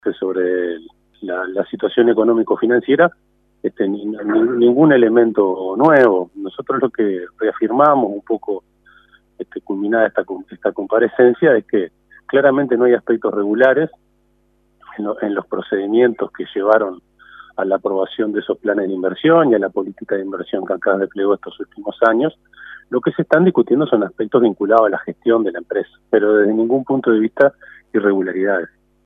El senador frenteamplista Marcos Otheguy, integrante de la Mesa Política, dijo a 810 Vivo que a instancias de los compañeros de bancada de De Los Santos, se resolvió desvincularlo de toda instancia orgánica del partido y pasar los antecedentes al tribunal de conducta política del Frente Amplio.